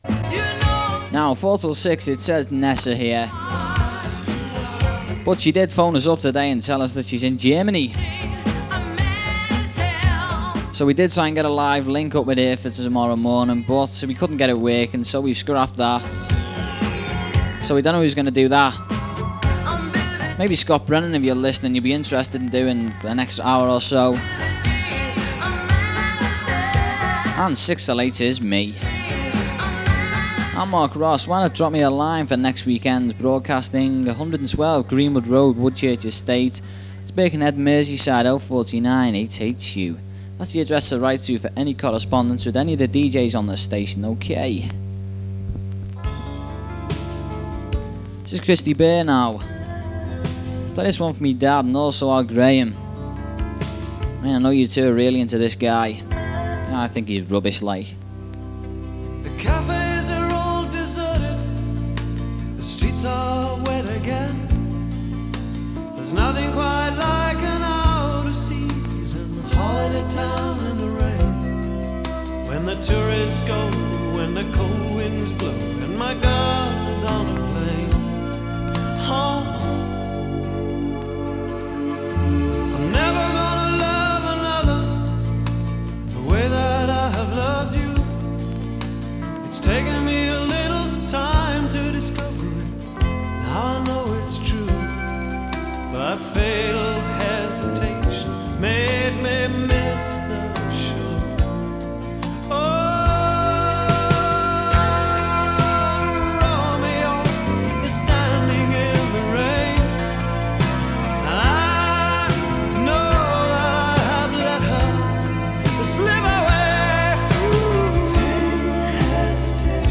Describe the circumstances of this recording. As received near Blackpool on 104.8MHz in mono.